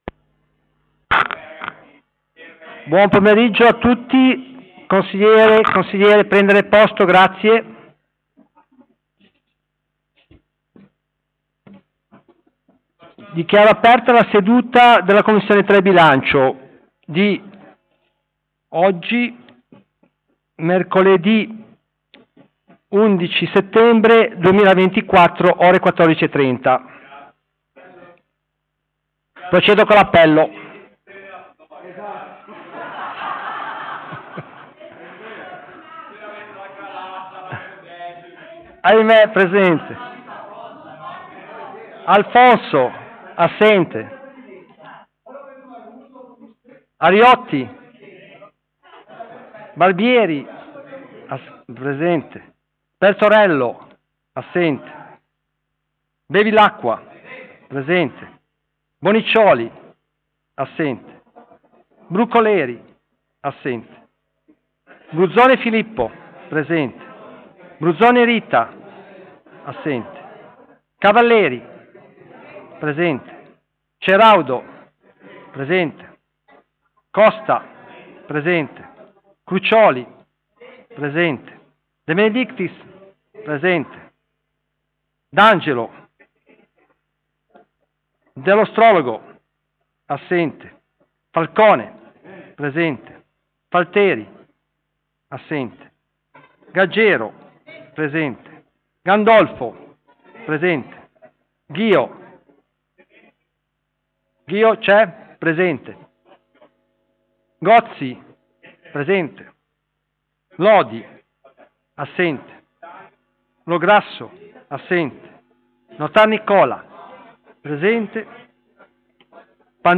Presso la Sala Consiliare di palazzo Tursi-Albini
Audio seduta: